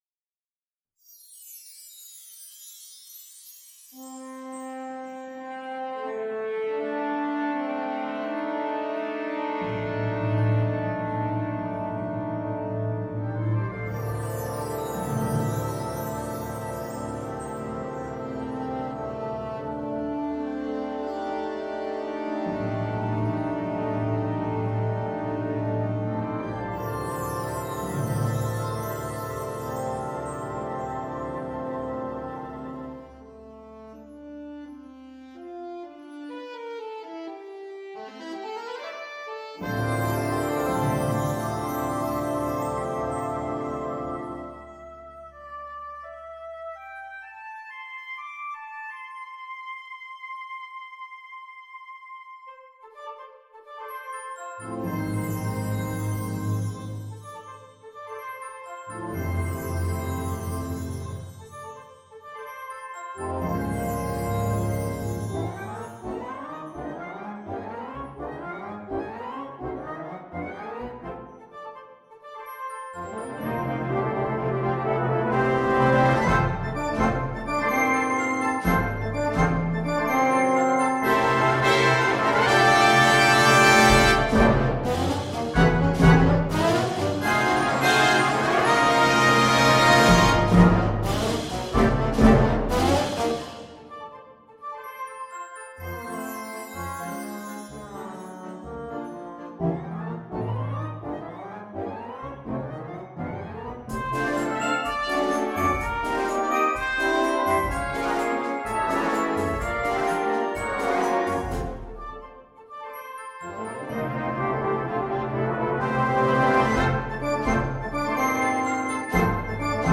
Instrumentation: Wind Band
is a superb symphonic sketch